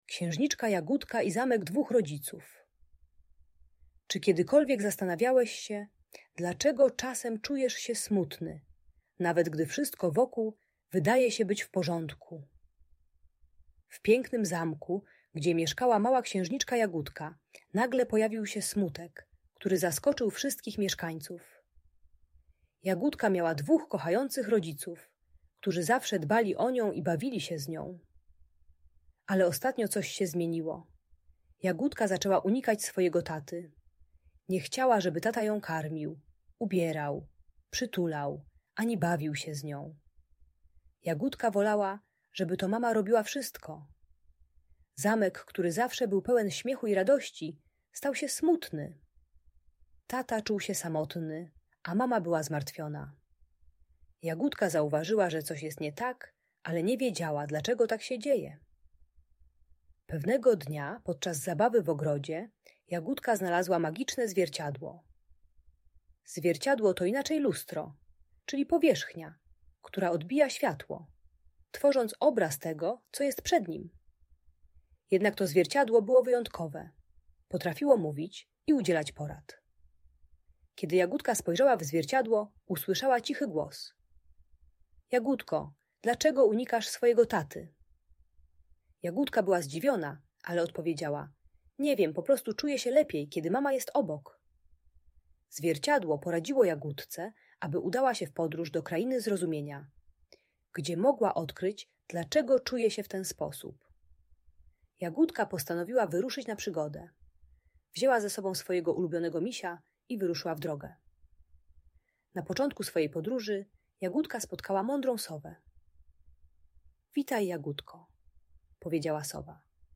Księżniczka Jagódka i Zamek Dwóch Rodziców - Audiobajka dla dzieci